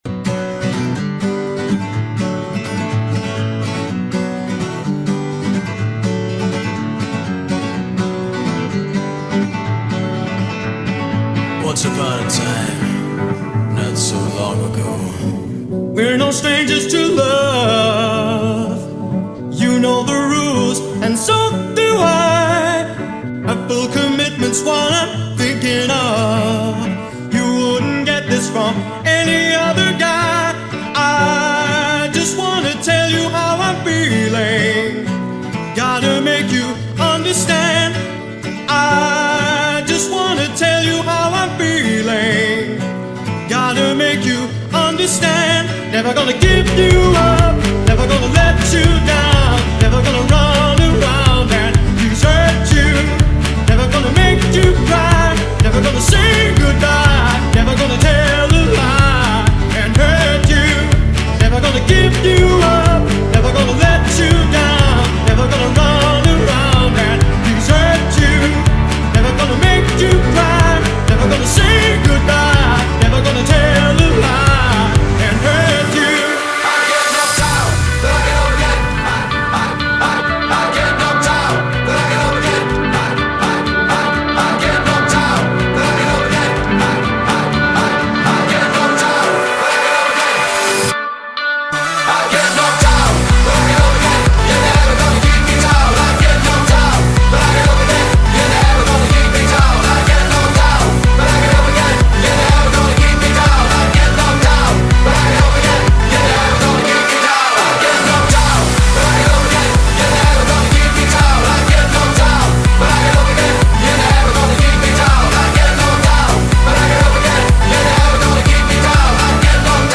BPM123-128
MP3 QualityMusic Cut